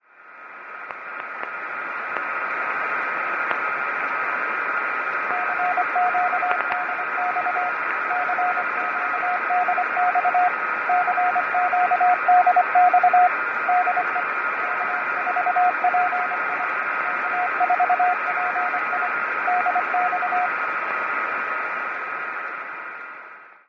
Bonne surprise en rentrant du travail, il y a de l’activité sur 6m, mais qu’en télégraphie.